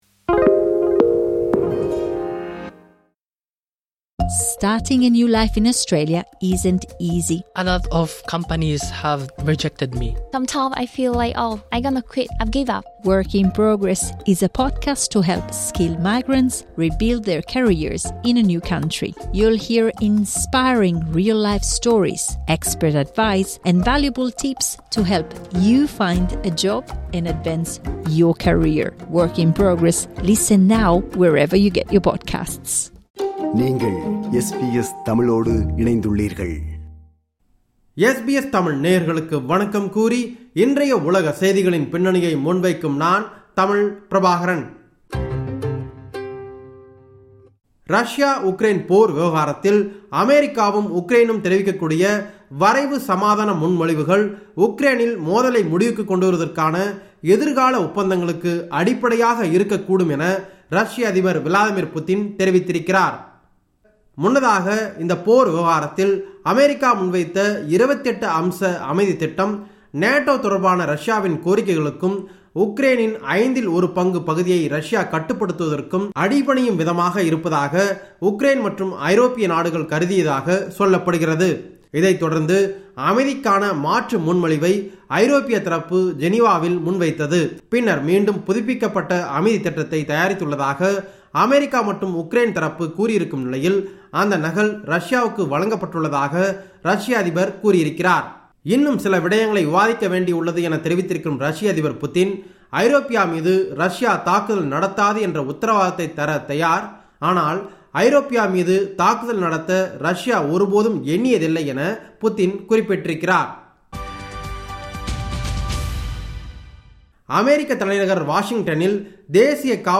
உலகம்: இந்த வார செய்திகளின் தொகுப்பு